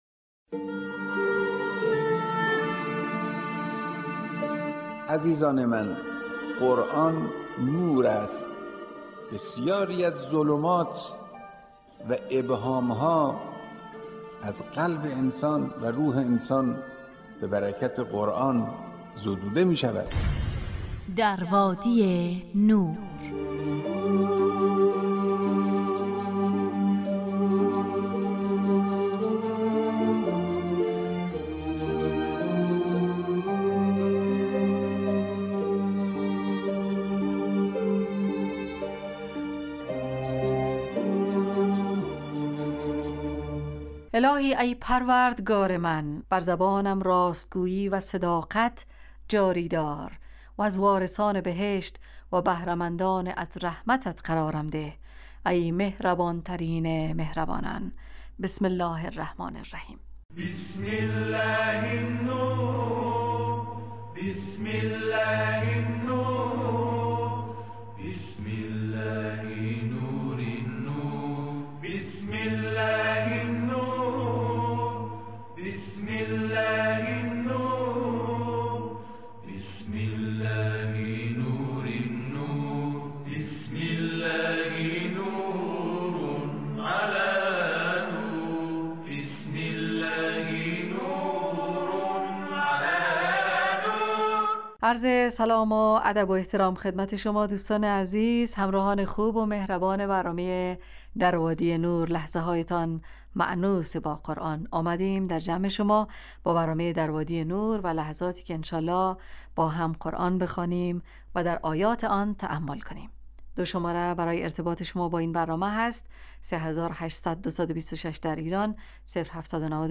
ایستگاه تلاوت